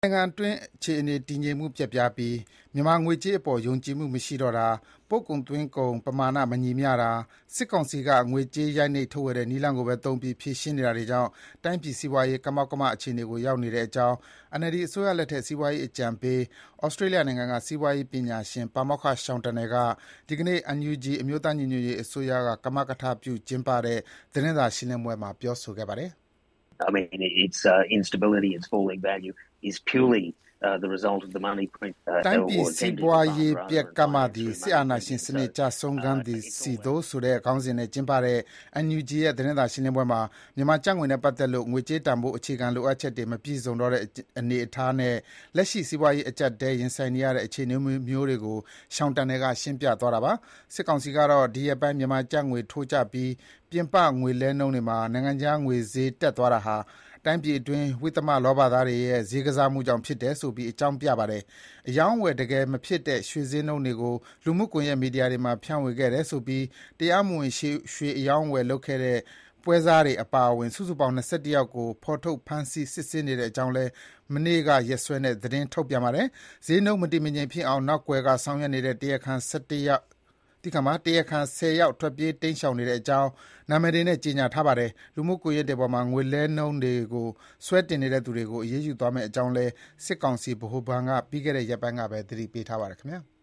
မြန်မာနိုင်ငံအတွင်း အခြေအနေ တည်ငြိမ်မှု ပျက်ပြားပြီး မြန်မာငွေကြေးအပေါ် ယုံကြည်မှုမရှိတော့တာ၊ ပို့ကုန်နဲ့ သွင်းကုန် ပမာဏ မညီမျှတာ၊ စစ်ကောင်စီက ငွေကြေး ရိုက်နှိပ် ထုတ်ဝေတဲ့ နည်းလမ်းကိုပဲသုံးပြီး ဖြေရှင်းနေတာတွေကြောင့် တိုင်းပြည်စီးပွားရေး ကမောက်ကမ အခြေအနေကို ရောက်နေတဲ့အကြောင်း NLD အစိုးရလက်ထက် စီးပွားရေးအကြံပေး၊ ဩစတြေးလျနိုင်ငံက စီးပွားရေးပညာရှင် ပါမောက္ခ Sean Turnell က ဒီနေ့ NUG အမျိုးသားညီညွတ်ရေးအစိုးရက ကမကထလုပ် ကျင်းပတဲ့ သတင်းစာရှင်းလင်းပွဲမှာ ပြောဆိုပါတယ်။